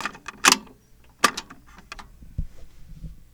Index of /90_sSampleCDs/E-MU Producer Series Vol. 3 – Hollywood Sound Effects/Miscellaneous/Cassette Door
CASSETTE 02L.wav